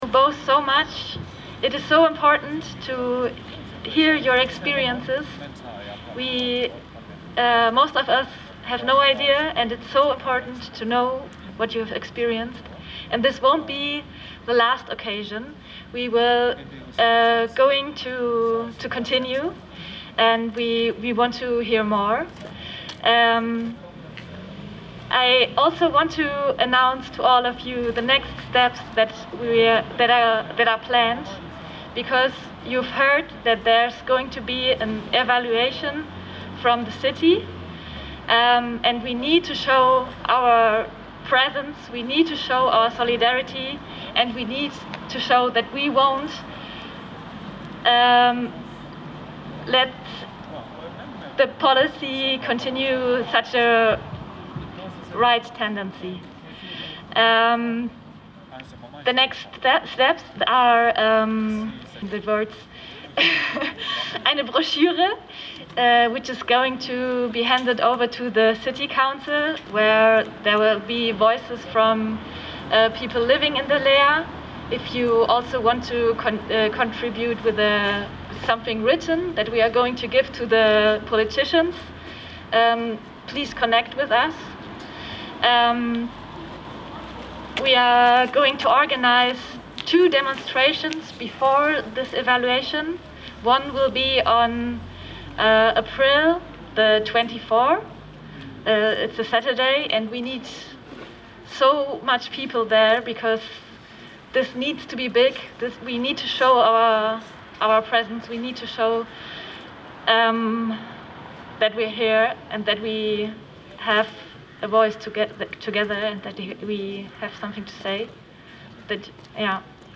Mahnwache vor der LEA in Freiburg